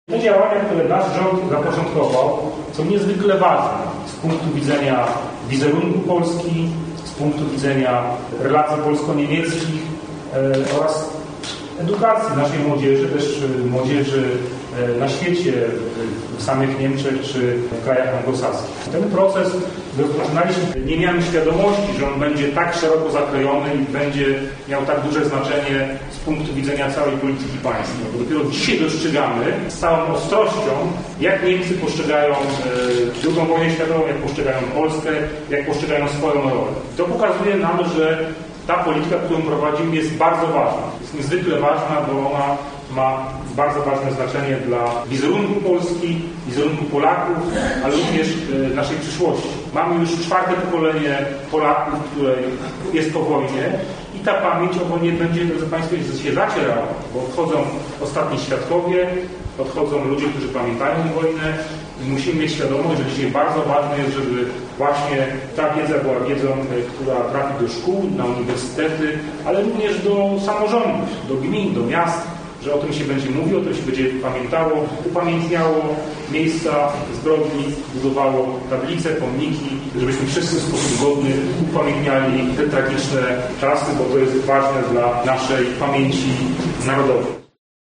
– mówił podczas spotkania z mieszkańcami Wielunia wiceminister Arkadiusz Mularczyk.